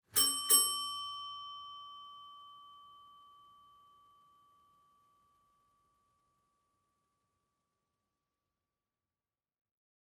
جلوه های صوتی
دانلود صدای زنگ در 3 از ساعد نیوز با لینک مستقیم و کیفیت بالا
برچسب: دانلود آهنگ های افکت صوتی اشیاء دانلود آلبوم صدای زنگ در خانه از افکت صوتی اشیاء